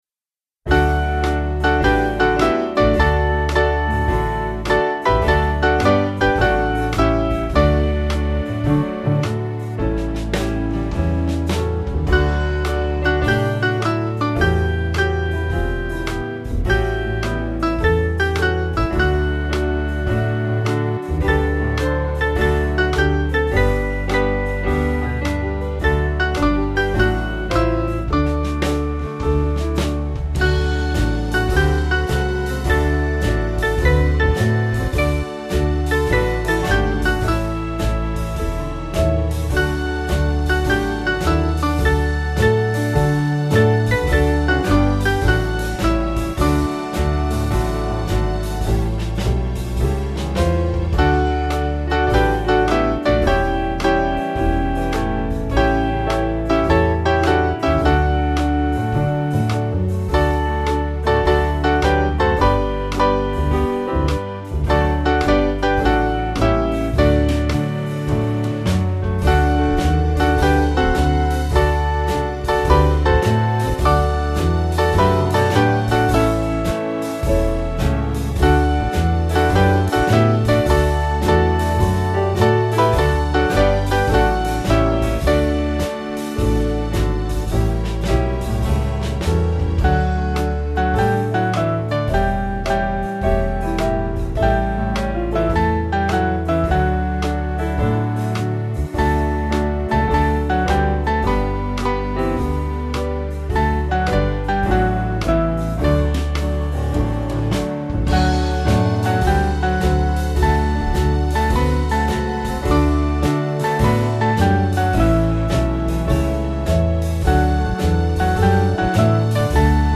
Swing Band